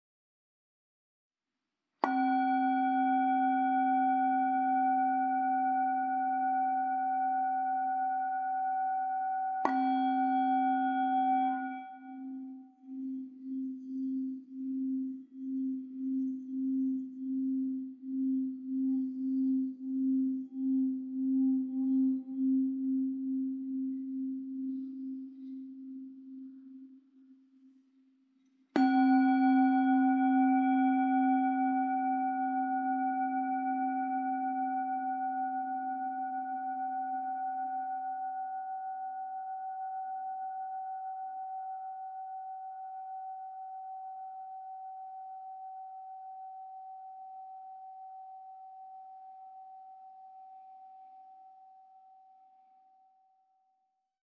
Meinl Sonic Energy Energy Therapy Series Singing Bowl - 700g (SB-E-700)
Durch sanftes Anschlagen oder Reiben erzeugen die Sonic Energy Klangschalen einen faszinierenden, vielschichtigen und farbenreichen Klang, der tief in die Seele hineinklingt. Über einem satten Grundton entstehen ganze Kaskaden von singenden Obertönen, die frei im Raum schweben und sich im Körper entfalten. Sobald der Klang zu schwingen beginnt, hört er nicht mehr auf; selbst nach einer Minute ist noch ein sanfter Nachhall zu spüren.